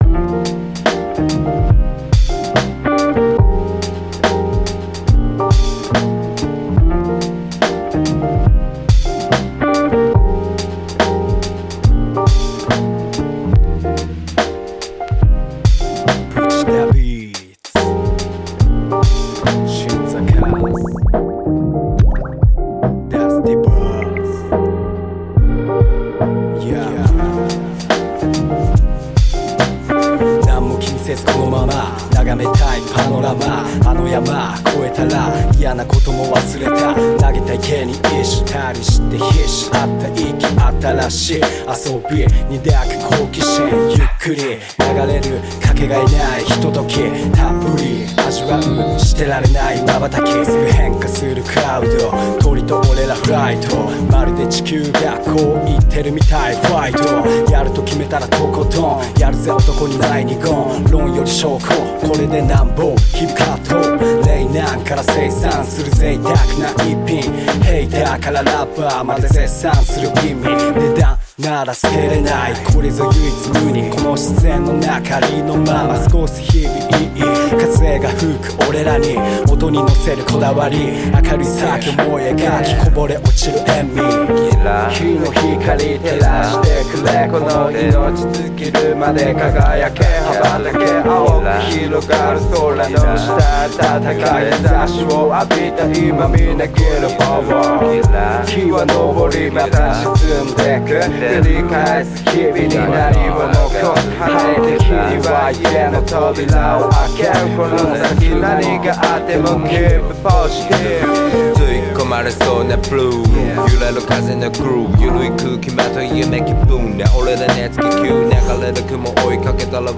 poet spoken words and reggae gospel artist.